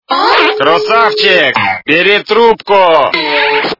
» Звуки » Люди фразы » Медвед - Кросавчег, бери трубко!
При прослушивании Медвед - Кросавчег, бери трубко! качество понижено и присутствуют гудки.